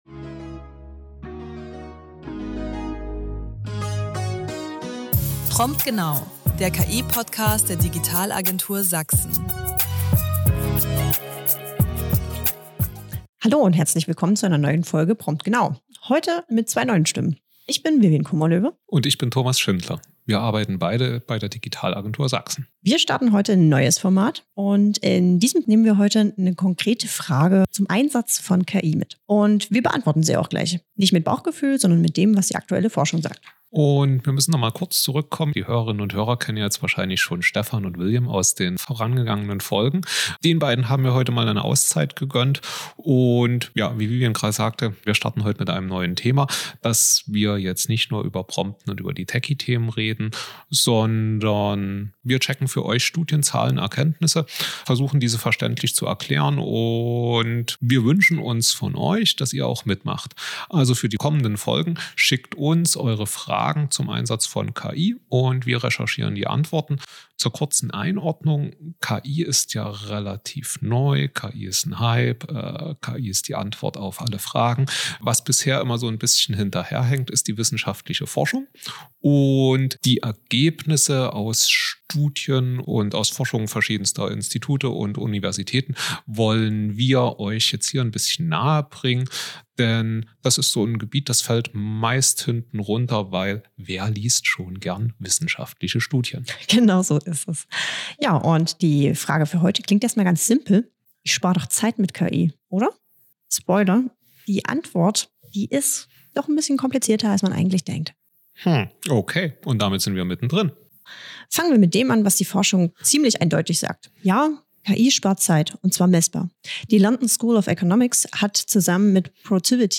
Zwei neue Stimmen, ein neues Format: